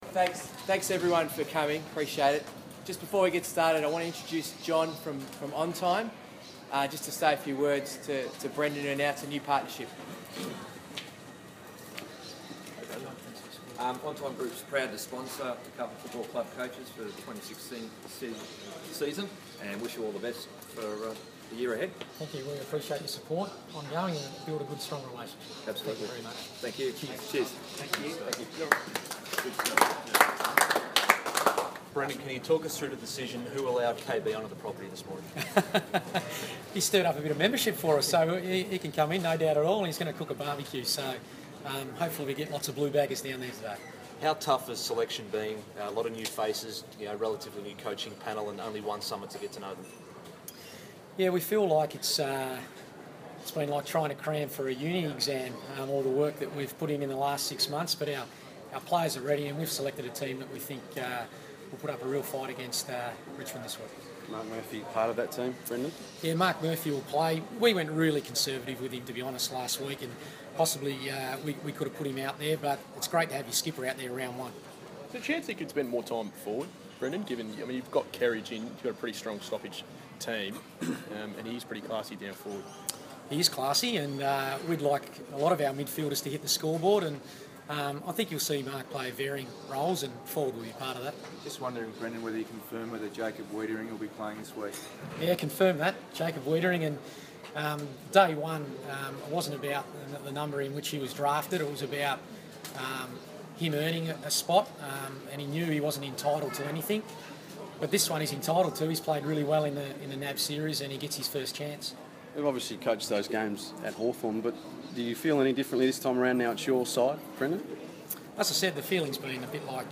Brendon Bolton press conference - March 23
Coach Brendon Bolton speaks to the media at Ikon Park ahead of Carlton's Round 1 clash against the Tigers.